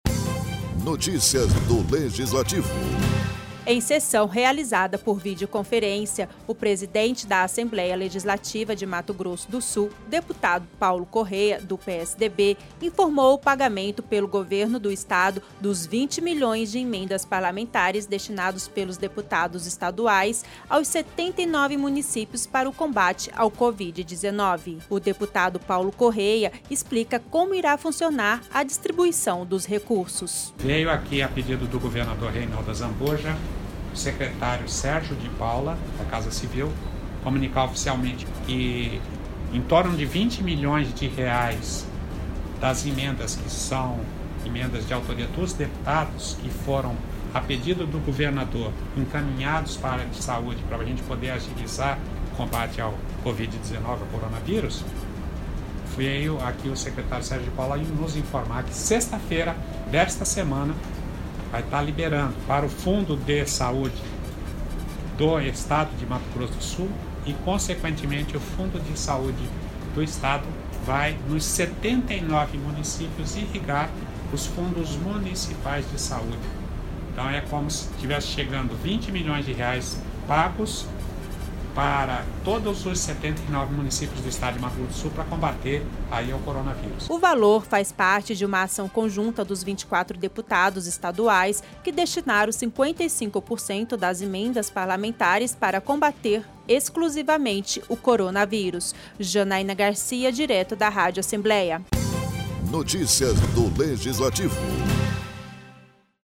Em sessão realizada por videoconferência desta quarta-feira, o presidente da Assembleia Legislativa, deputado Paulo Corrêa, do PSDB, informou o pagamento dos R$ 20 milhões em emendas parlamentares aos 79 municípios.